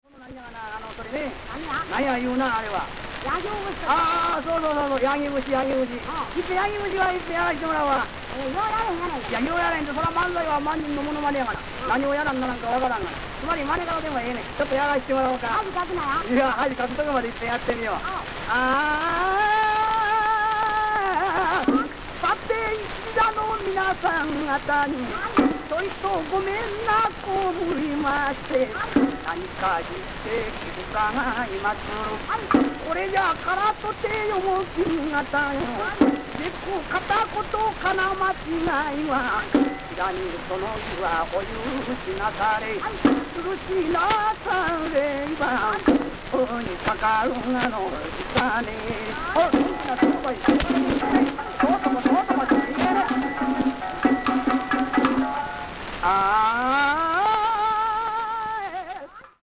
TRESURED 78RPM RECORD SOURCE
萬歳　八木節